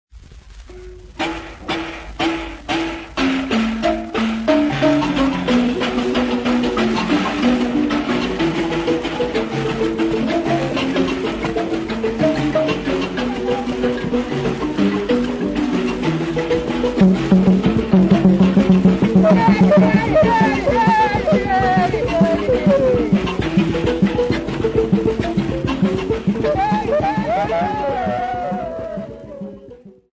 Nine Njari Mbira players
Folk Music
Field recordings
sound recording-musical
Indigenous music